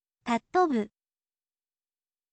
คำตัวอย่าง: たっとぶ
tattobu, ทะ โทะบึ